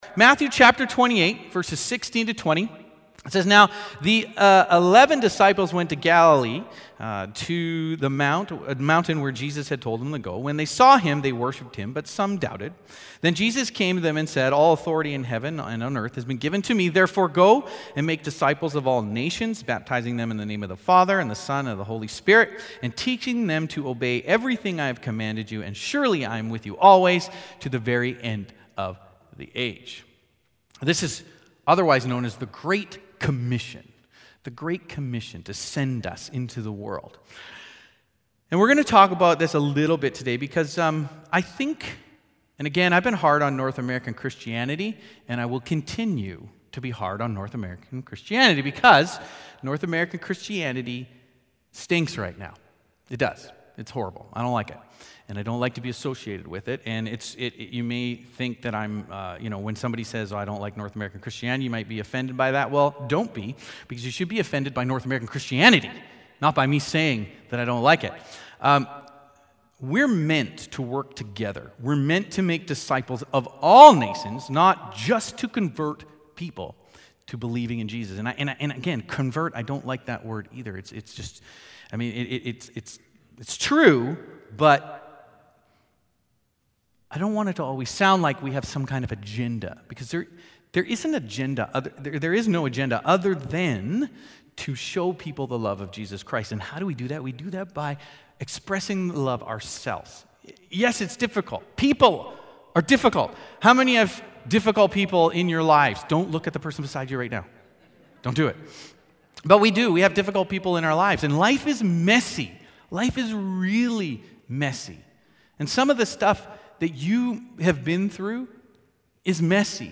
Sermons | Westwinds Community Church